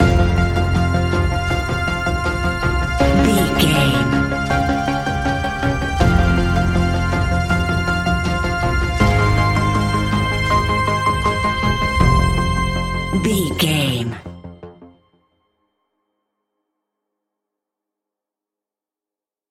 royalty free music
Aeolian/Minor
DOES THIS CLIP CONTAINS LYRICS OR HUMAN VOICE?
ominous
dark
eerie
industrial
synthesiser
drums